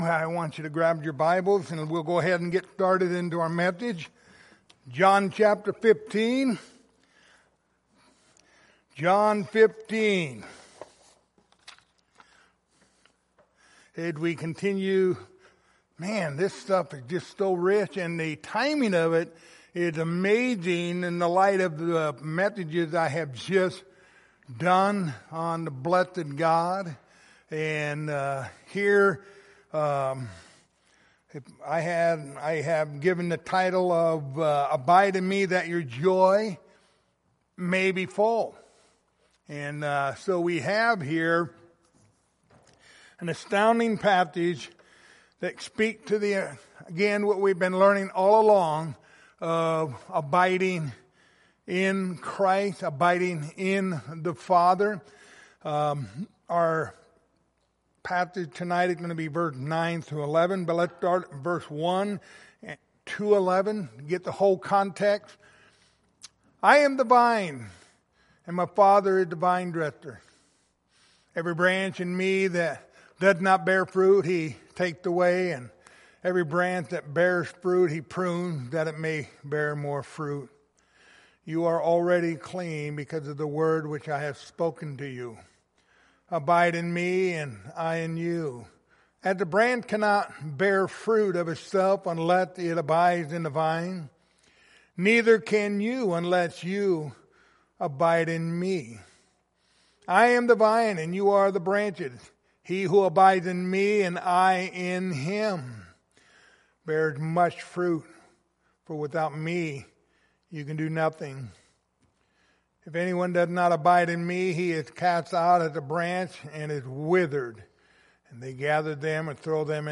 Passage: John 15:9-11 Service Type: Wednesday Evening